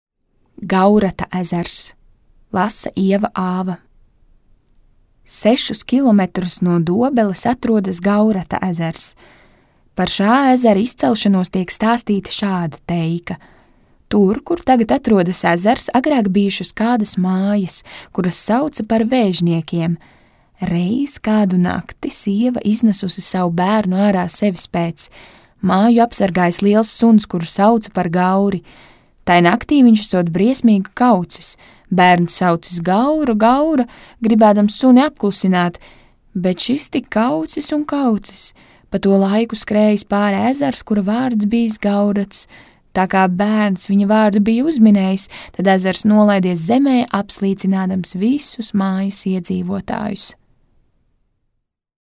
Teikas